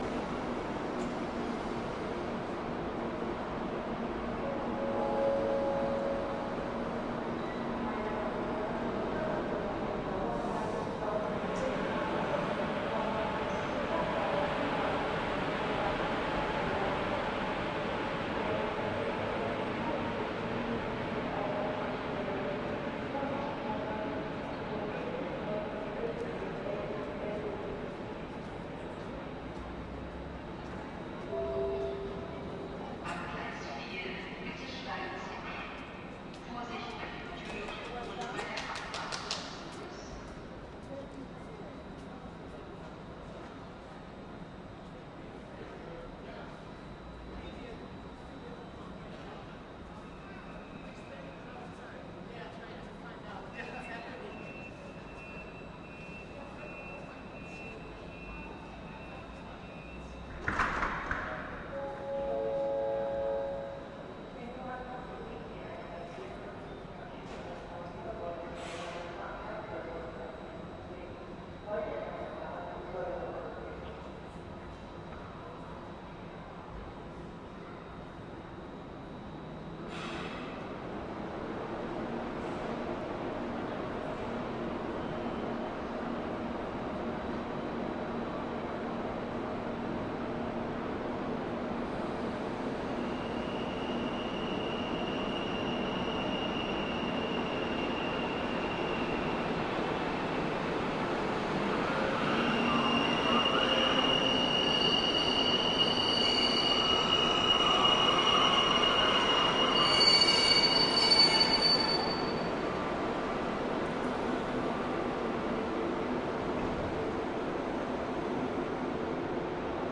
描述：在柏林的Spandau车站进行现场录音。火车到达和离开。
标签： 旅行 旅行 施潘道 柏林 公共交通 现场录音
声道立体声